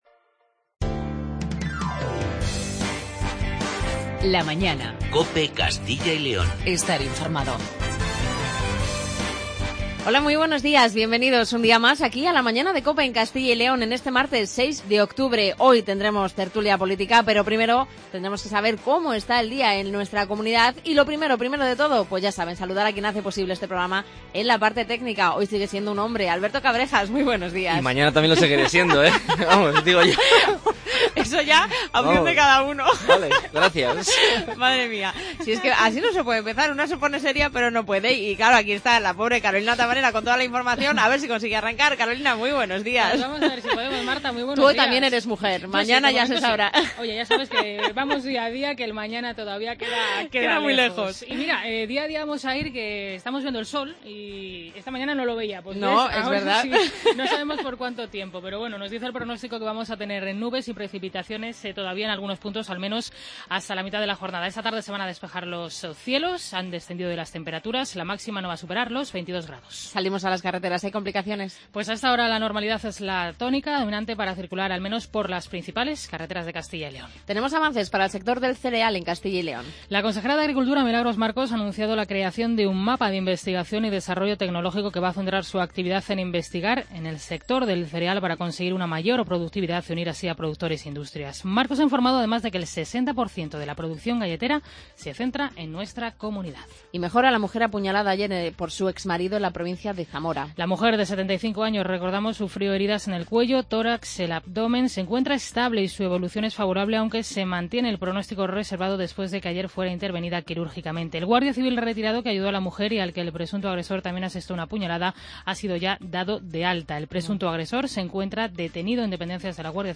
Tertulia Política